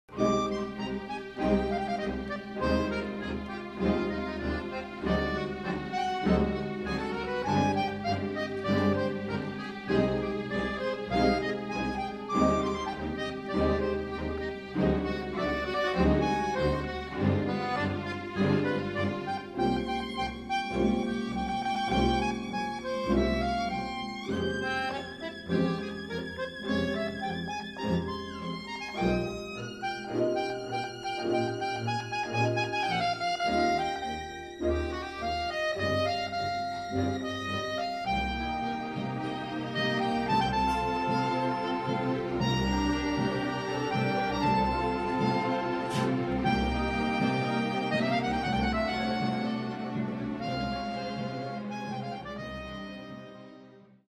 Live Accordion Recordings
Allegro tranquillo